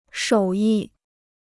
手艺 (shǒu yì): craftmanship; workmanship.